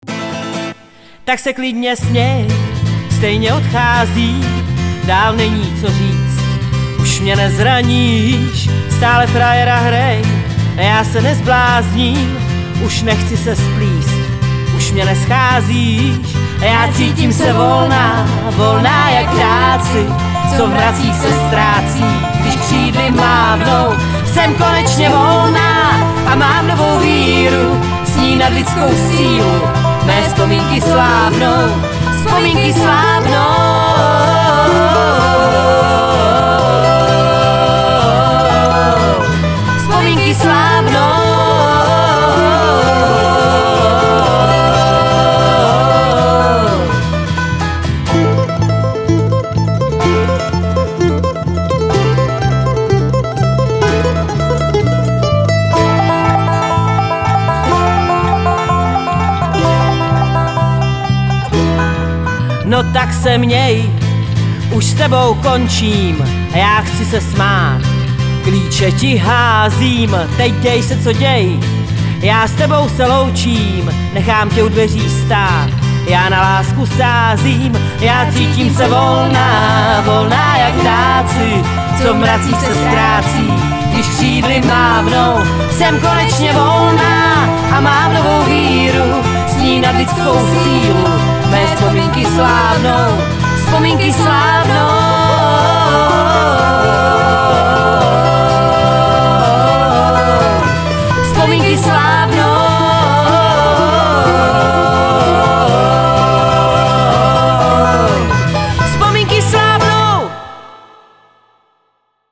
Folk / Country